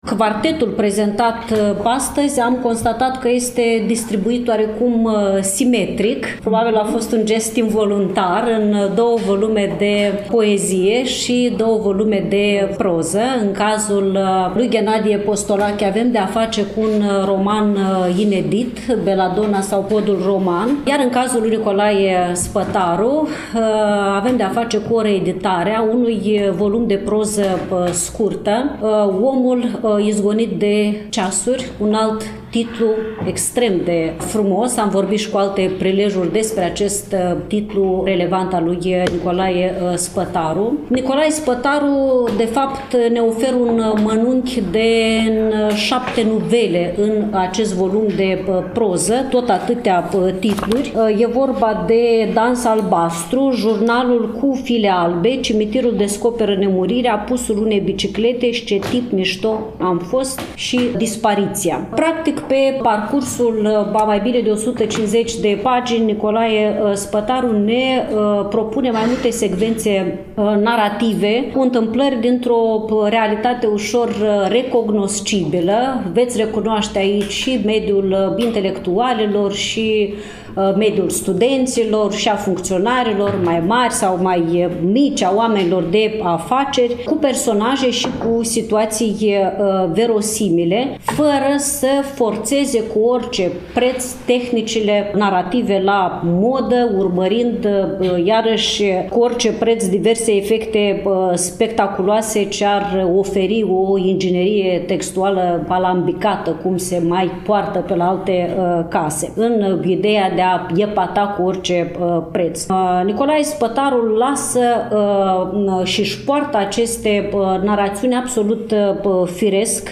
Cărțile au fost prezentate, la Iași, în ziua de joi, 21 noiembrie 2024, începând cu ora 14, în incinta sediului Editurii Junimea din Parcul Copou